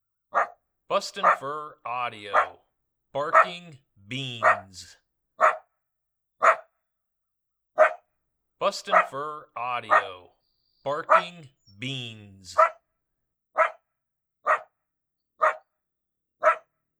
Adult Female Coyote barks, excellent sound to combine with coyote social and fight sounds.